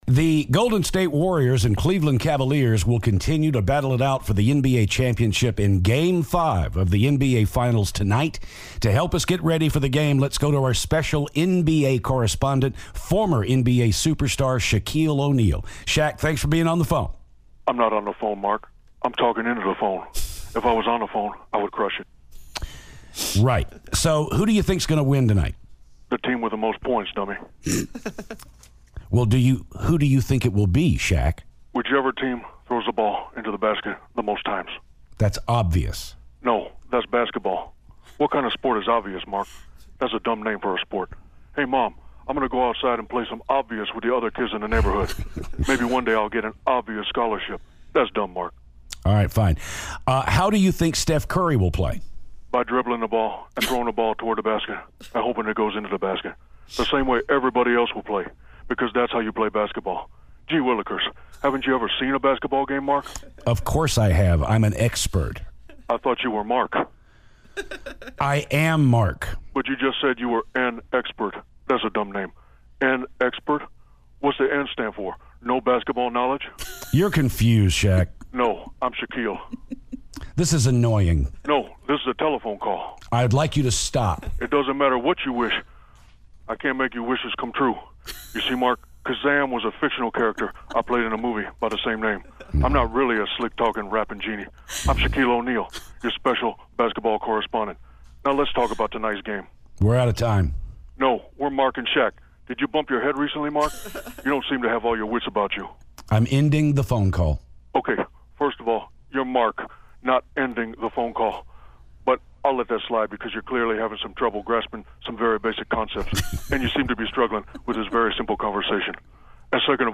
Shaquille O'Neal Phoner